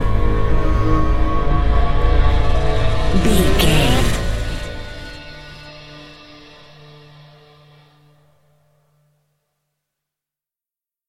Thriller
Aeolian/Minor
Slow
piano
synthesiser
electric guitar
ominous
dark
suspense
haunting
tense
creepy
spooky